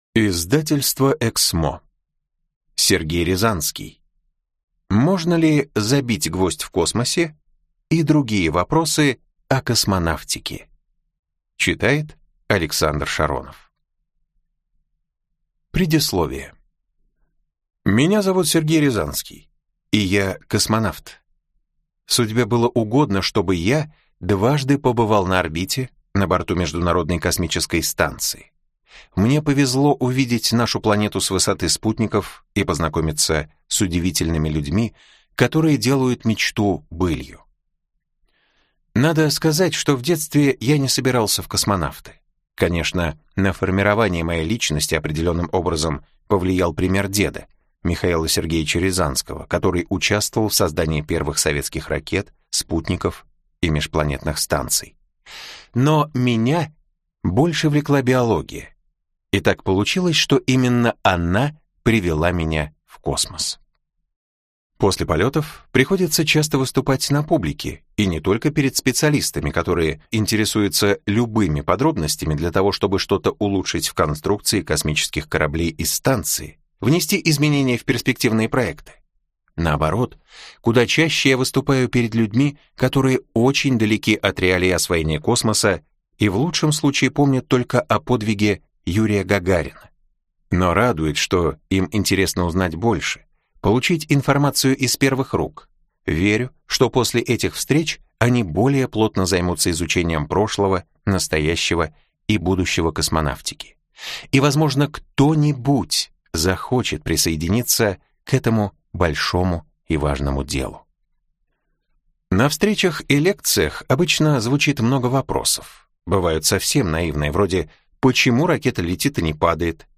Аудиокнига Можно ли забить гвоздь в космосе и другие вопросы о космонавтике | Библиотека аудиокниг